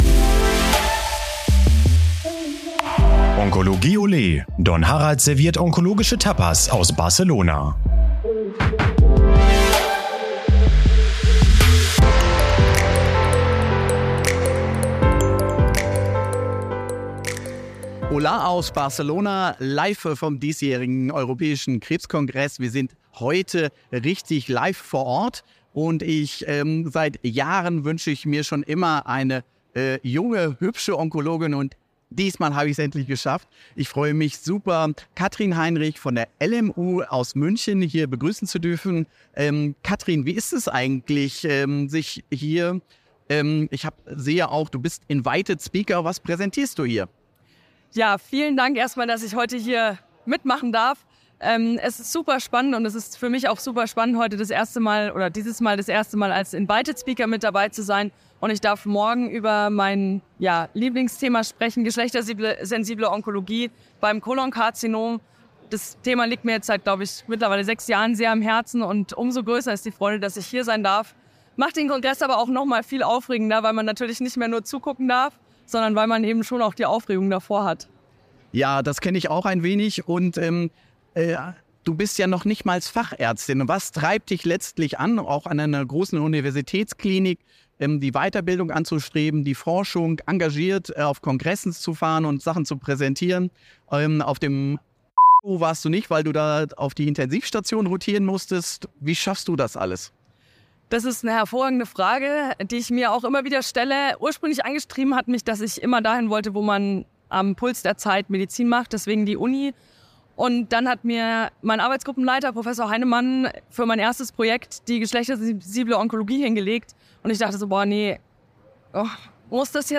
Am Mikrofon teilt sie live aus den Kongresshallen in Barcelona ihre Erkenntnisse über die geschlechtersensible Onkologie und die Herausforderungen der klinischen und wissenschaftlichen Arbeit.